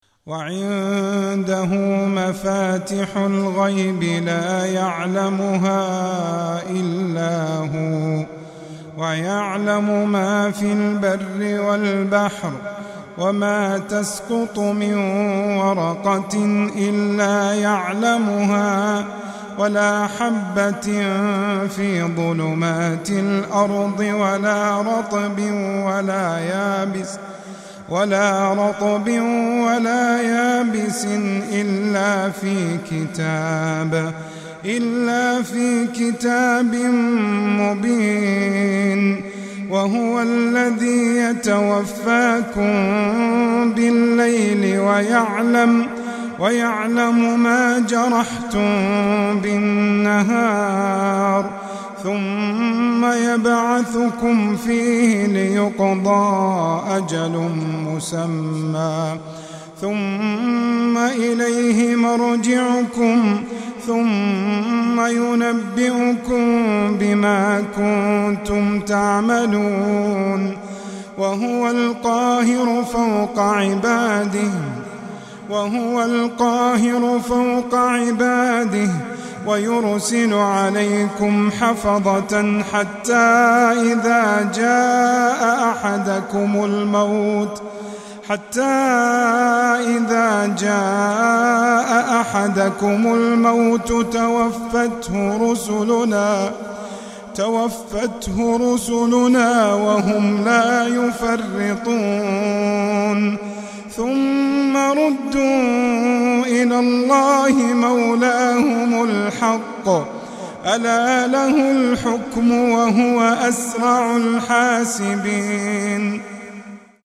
تلاوة من سورة الأنعام